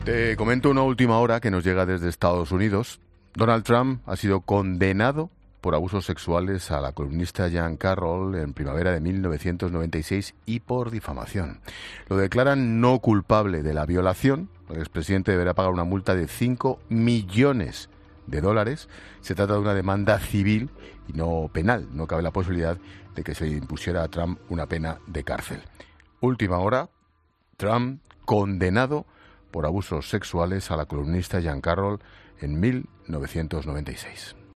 Te da más detalles el director de 'La Linterna', Ángel Expósito